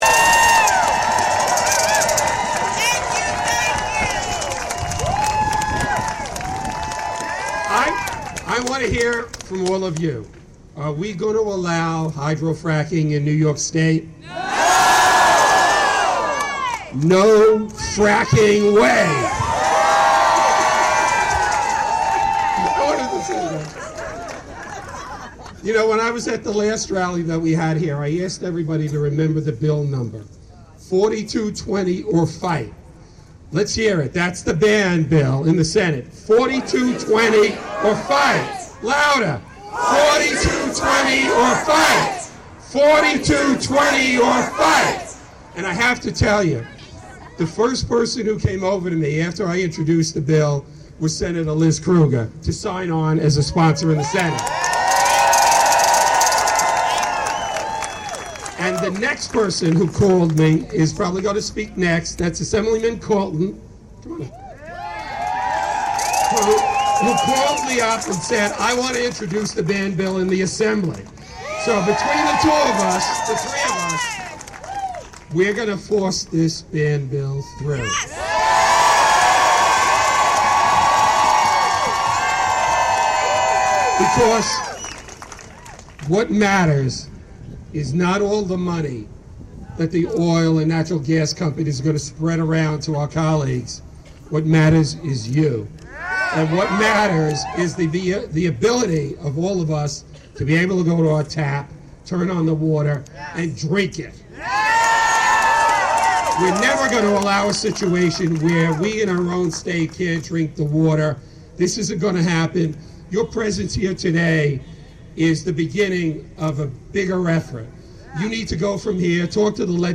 NY State Senators Tony Avella and Liz Krueger, and Assmblyman William Colton, speaking at Albany Earth Day May 2 about hydrofracking bill they are sponsoring. (Audio)